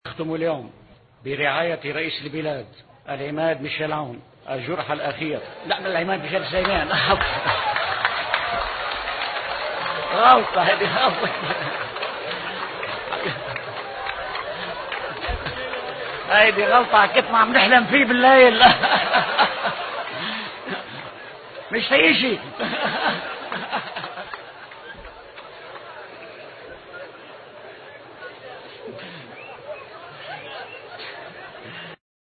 ينتشر على وسائل التواصل الاجتماعي شريط فيديو للنائب وليد جنبلاط يعلن فيه أنّ رئيس البلاد هو العماد ميشال عون، وذلك إثر خطاب